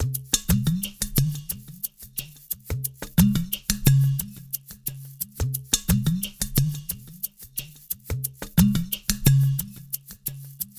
SMP PERCMX-L.wav